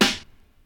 • Old School Rap Snare Drum Sample G# Key 57.wav
Royality free acoustic snare sample tuned to the G# note. Loudest frequency: 2618Hz
old-school-rap-snare-drum-sample-g-sharp-key-57-Wpn.wav